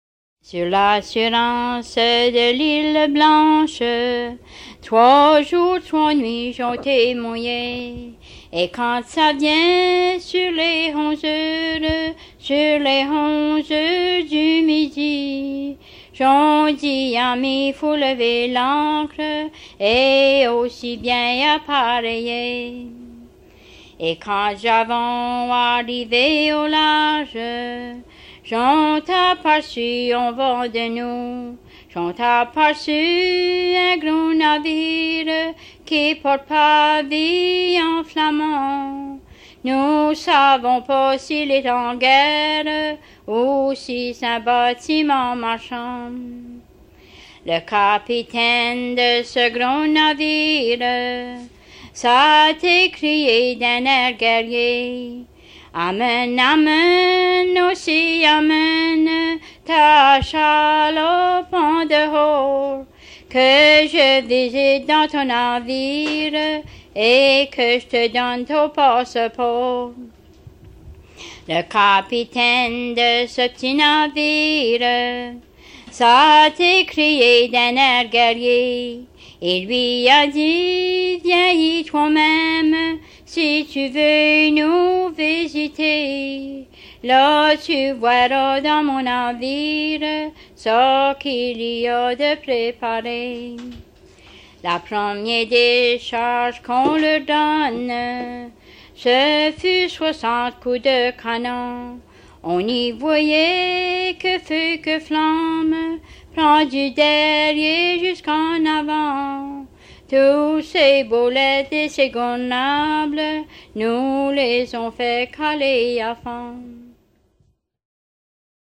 sur l'île-du-Prince-Edouard, enregistrée en 1975
Thème : 0071 - L'armée - Marins
Genre strophique